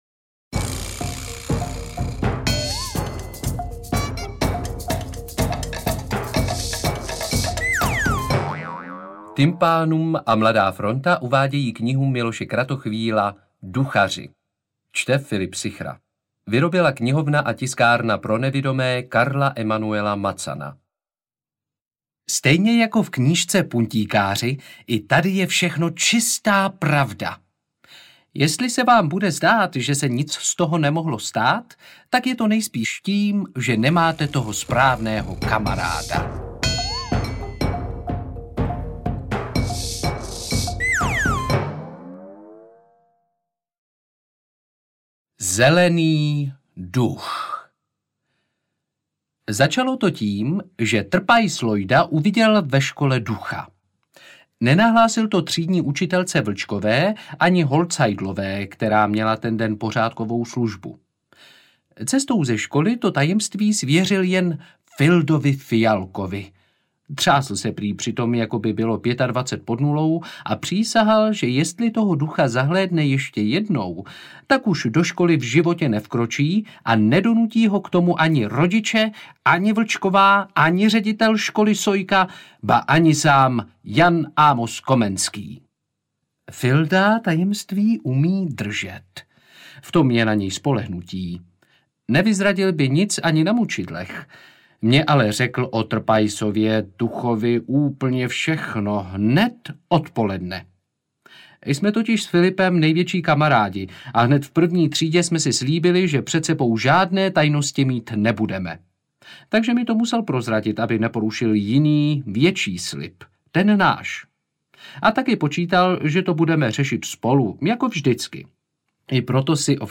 AudioKniha ke stažení, 39 x mp3, délka 3 hod. 2 min., velikost 165,1 MB, česky